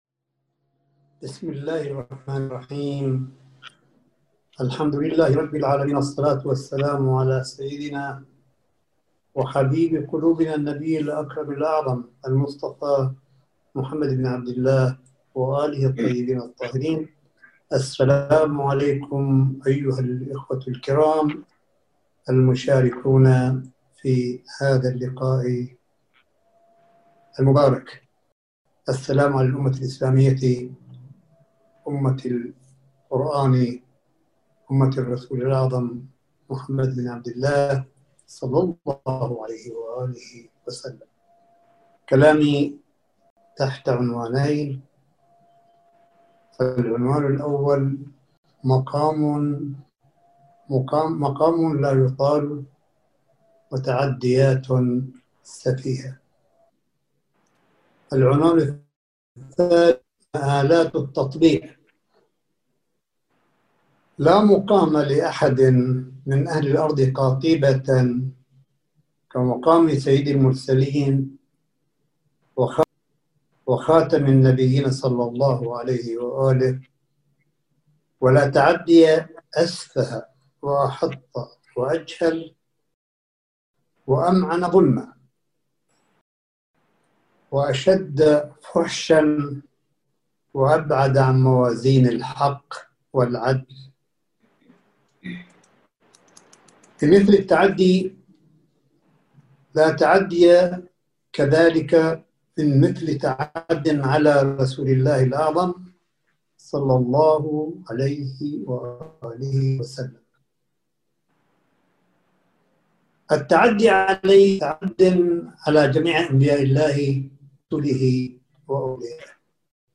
ملف صوتي لكلمة سماحة آية الله الشيخ عيسى أحمد قاسم في مؤتمر كبار العلماء استنكاراً لمحاولة الاساءة للرسول الأعظم(ص) وحرق القرآن، وتصدياً لاتفاقيّة العار والخيانة، وذلك عبر مؤتمرٍ مرئي الفضاء المجازي.